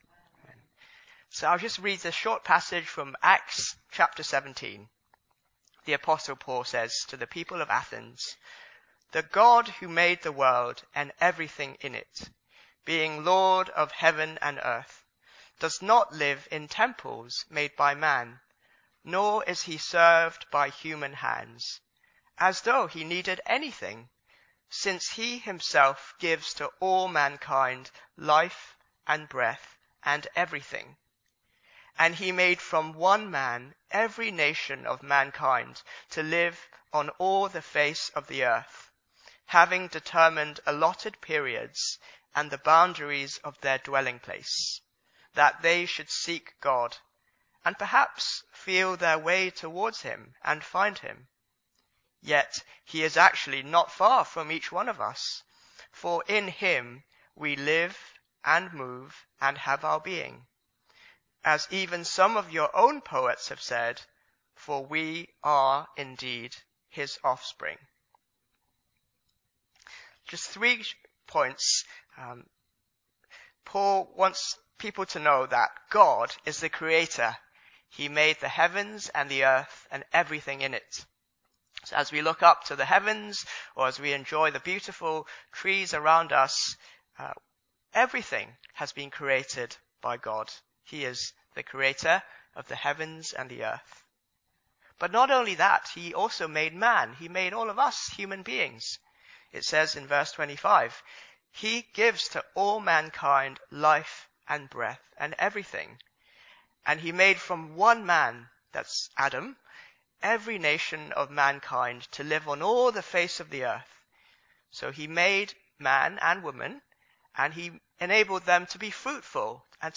Into all the world Sermon Search media library...